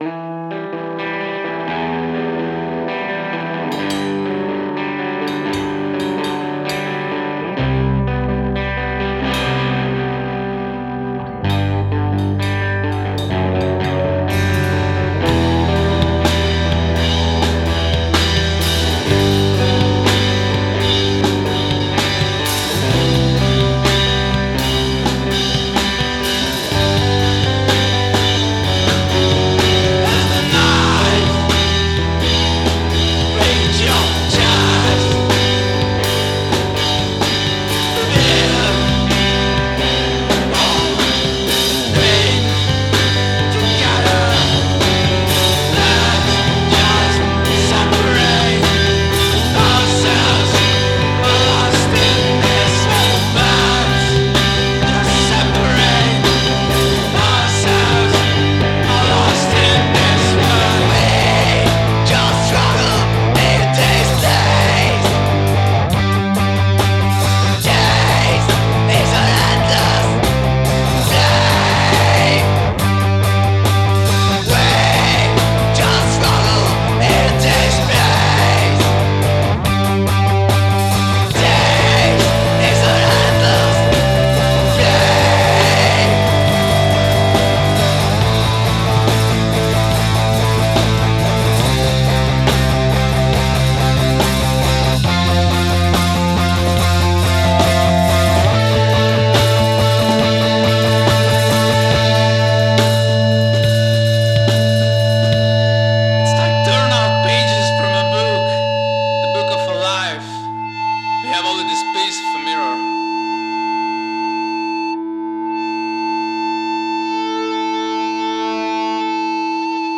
DIY screamo/punk song.
I play in a band and have recorded our first demo. FYI This is a DIY project, we recorded everything in an untreated reharsal room with budget gear. Because of the genre of our music, I don't want nor need a polished sound, it's better off raw and noisy.